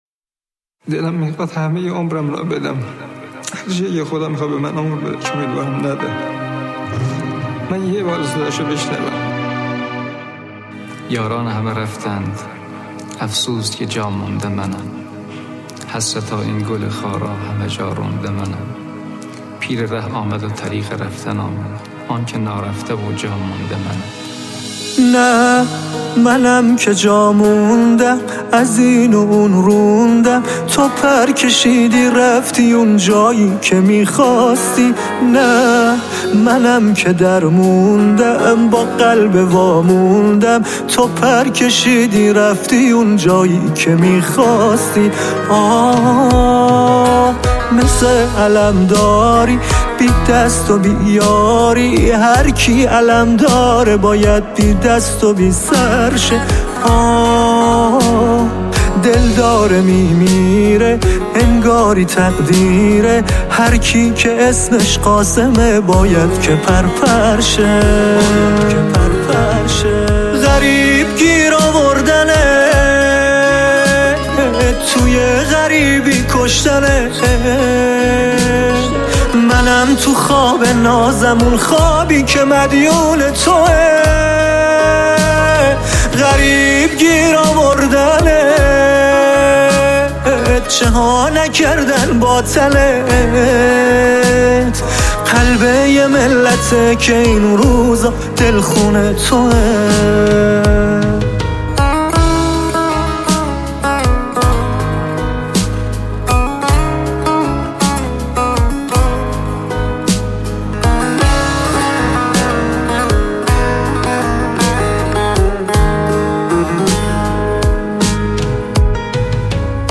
آهگ غمگین و سوزناک برای سلیمانی